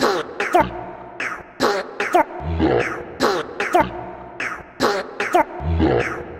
未来的低音主音 150 BPM 调 A Sharp
描述：用Nexus制作的钥匙是A。
标签： 150 bpm Trap Loops Vocal Loops 1.08 MB wav Key : A
声道立体声